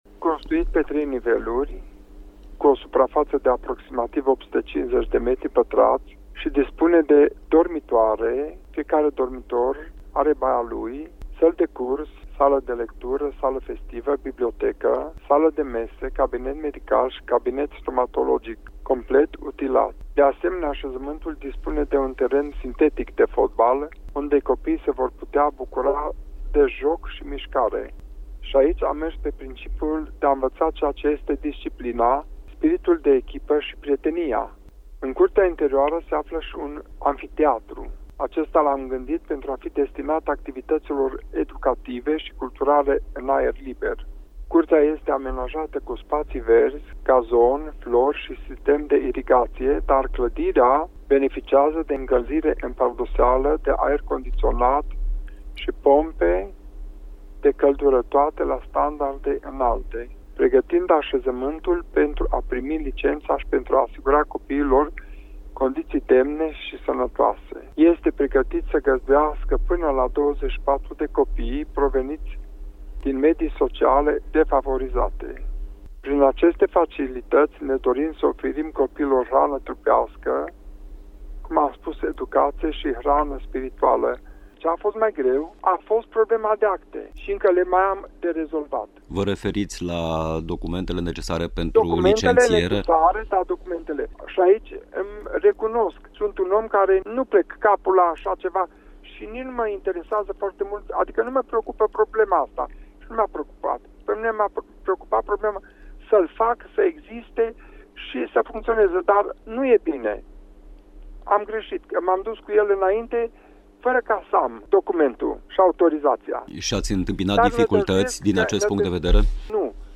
Interviul integral, duminică, 19 octombrie, de la ora 17:00, în „Triunghiul Întrebărilor”, pe frecvențele Radio Cluj sau online, aici: